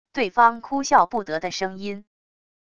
对方哭笑不得的声音wav音频